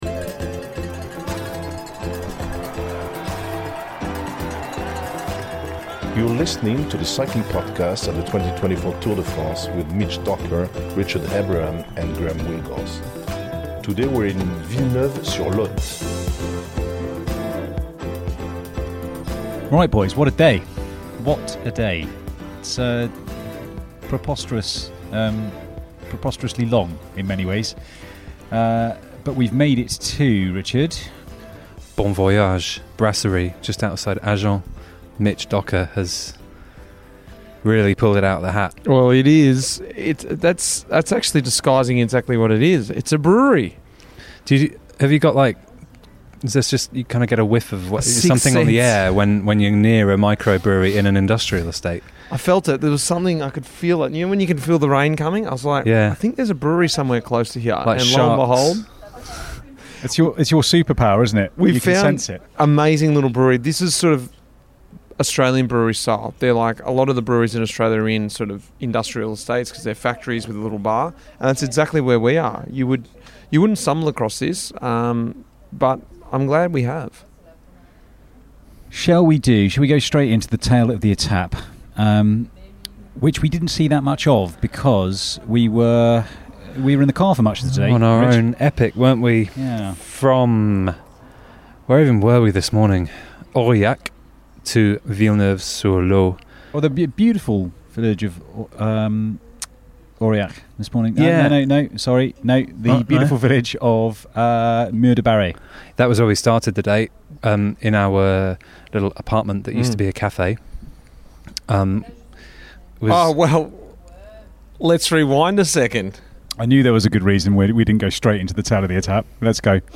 With a new line-up for the 2024 Tour, there’ll be the familiar mix of lively discussion, race analysis, interviews from Outside the Team Bus and some French flavour.